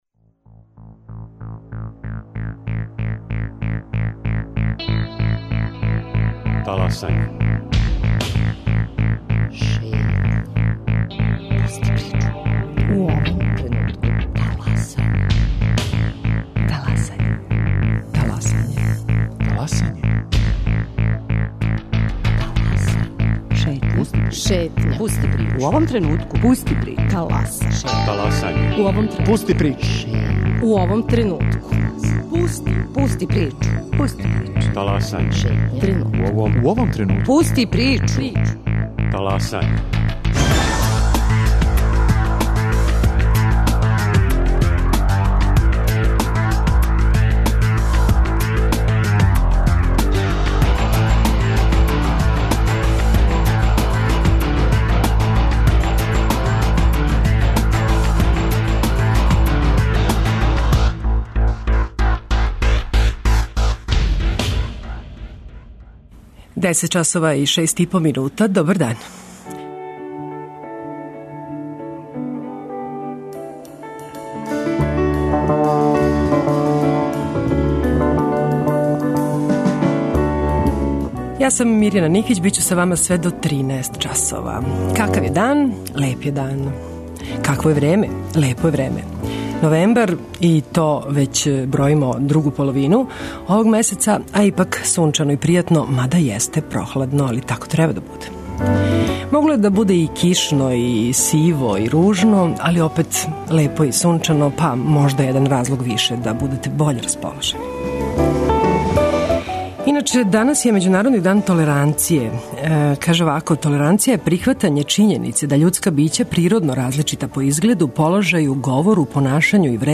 Гост емисије је Татјана Пашић, заменик градоначелника Београда.